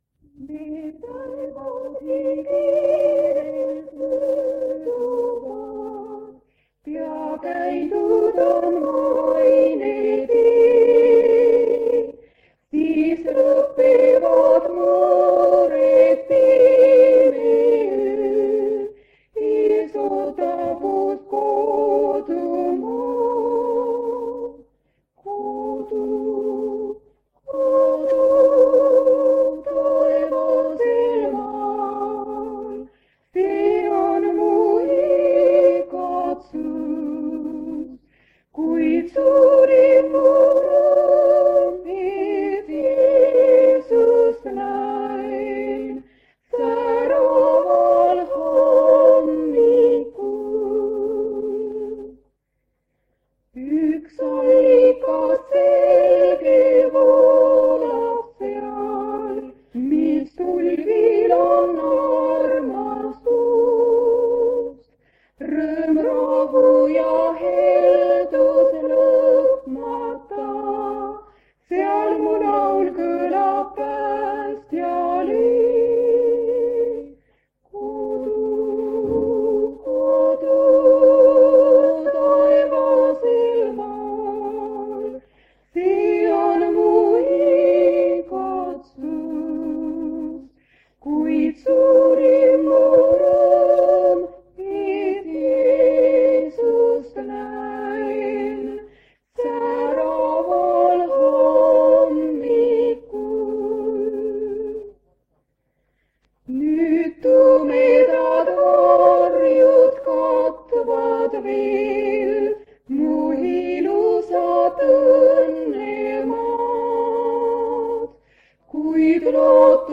Jutlus vanalt lintmaki lindilt.
Ja toimumas ilmselt evangeeliuminädal Kingissepa adventkoguduses.
Enne jutlust on ka nais-duetilt laul ja üks õde loeb luuletuse.